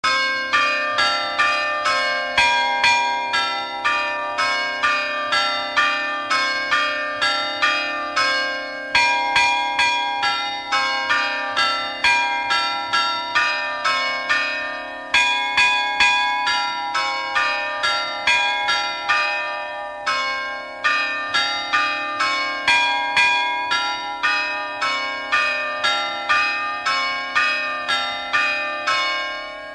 Air de carillon
Résumé instrumental
Pièce musicale inédite